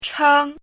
chēng
chēng.mp3